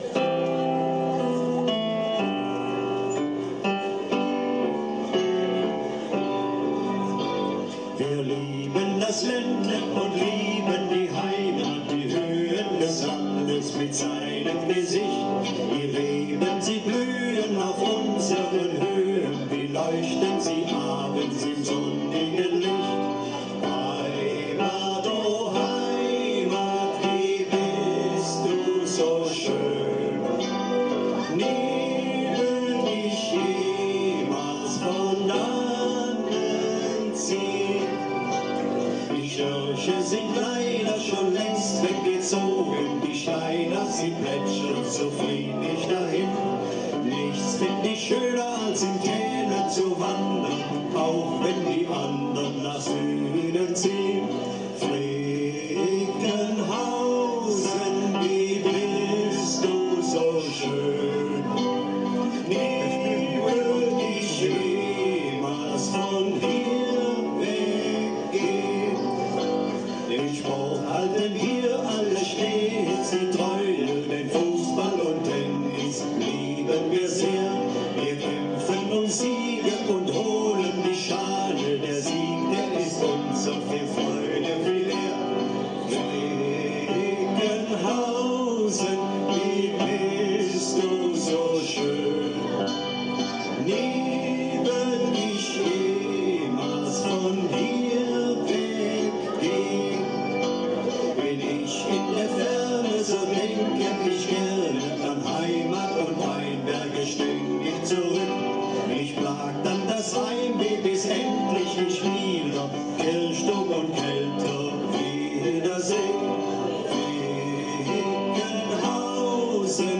Gesang
Piano